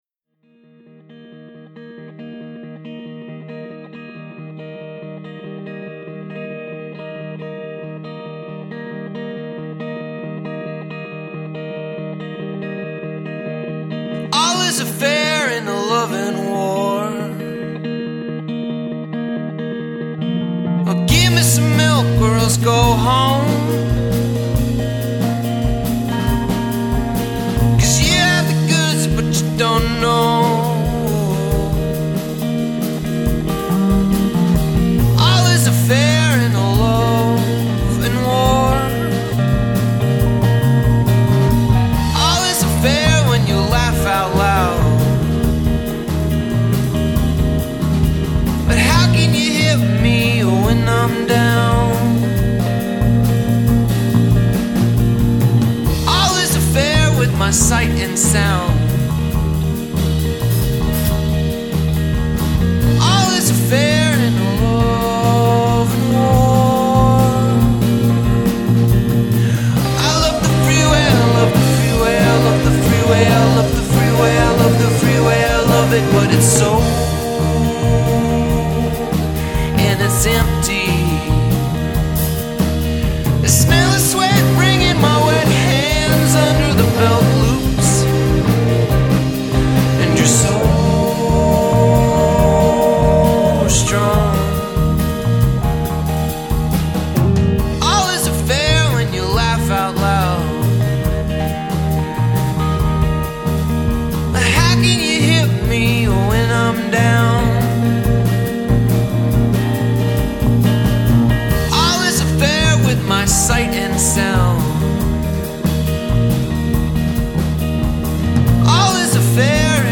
guitar and vocals
violin